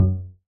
bassattack.ogg